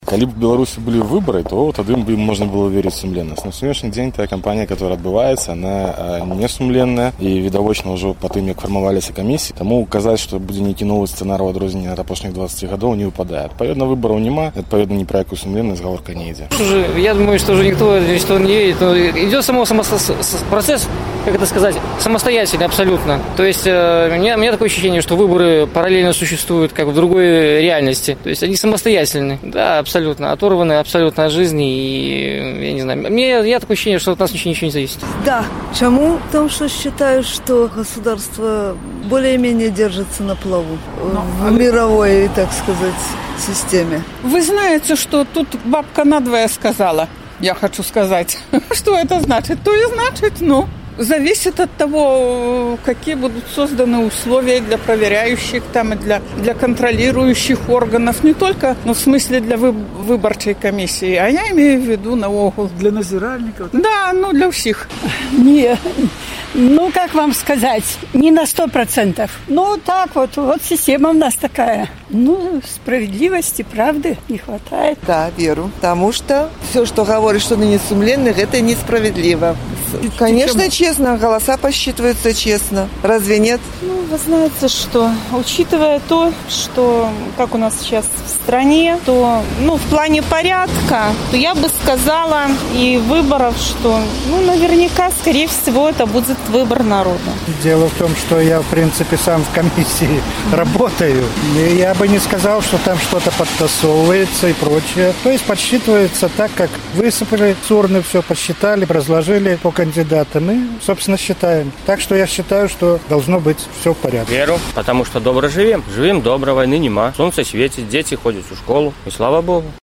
Vox populi
«Выбары ідуць паралельна з рэальным жыцьцём»,— апытаньне ў Горадні
З такім пытаньнем наш карэспандэнт зьвяртаўся да гарадзенцаў.